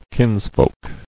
[kins·folk]